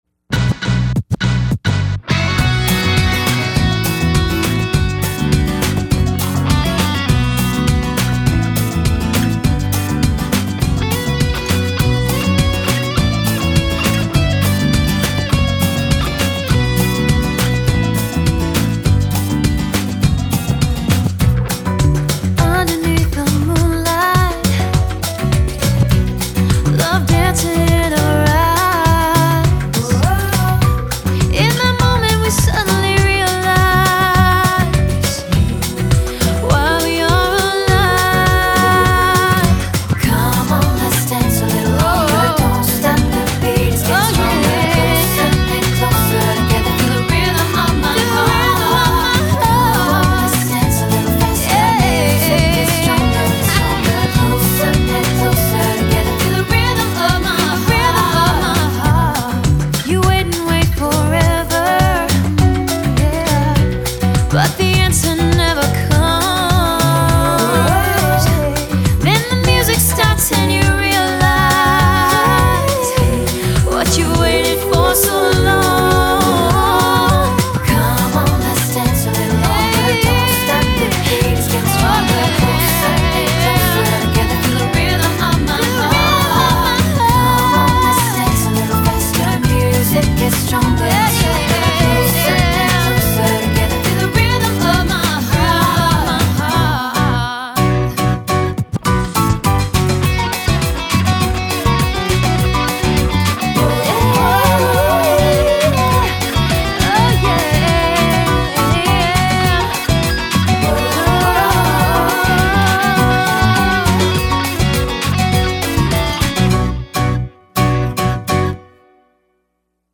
BPM102
MP3 QualityMusic Cut